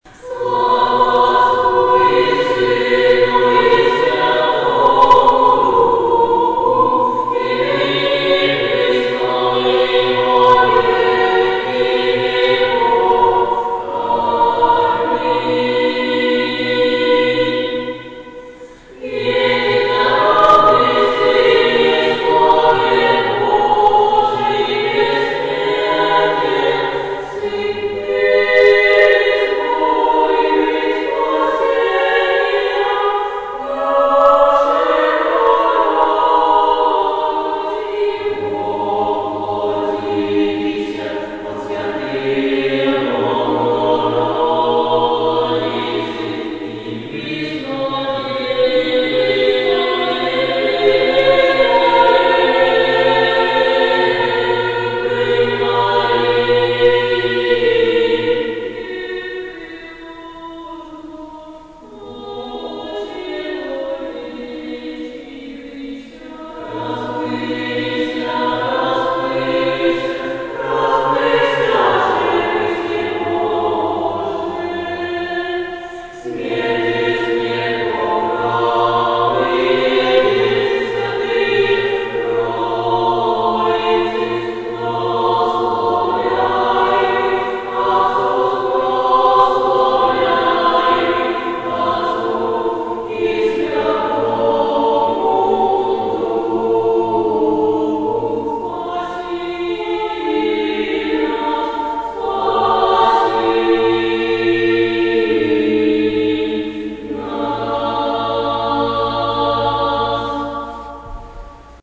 Немало лет в храме пела небольшая группа детей фольклорного ансамбля "Калинушка". Ниже мы привели записи песнопений, которые исполнили дети в восстановленном храме, осенью 2007 года.
Возможно, запись кого-то не устроит: все же это любительская запись. Мы постарались сохранить на будущее голоса детей, которые уже учатся в институтах.
2007-child-edinorod.mp3